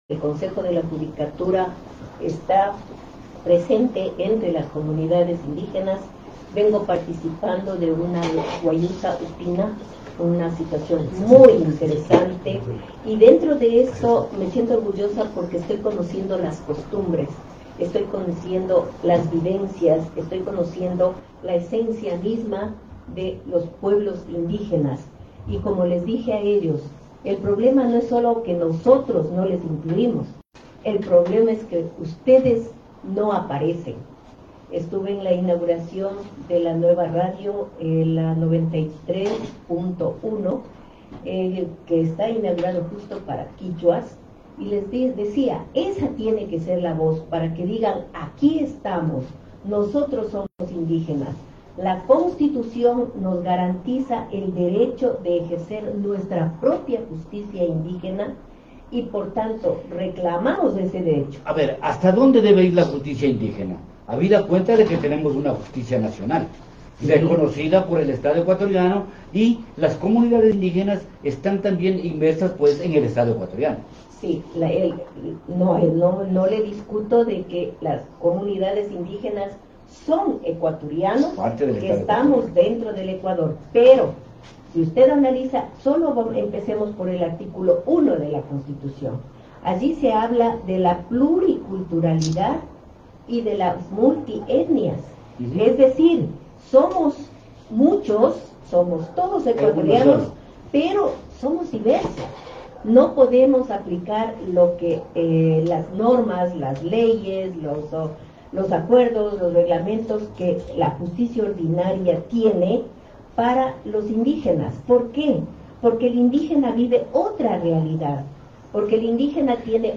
Dra. Yolanda Yupangui, vocal del Consejo de la Judicatura.
Una agenda de charlas, conferencias y entrevistas cumplió los últimos días en la Amazonía, Yolanda Yupangui, vocal del Consejo de la Judicatura (CJ); en Puyo Pastaza el 28 de marzo de 2025, previo a un encuentro con la ciudadanía para hablar de la aplicación de la justicia indígena, concedió una entrevista en Nina Radio de Puyo.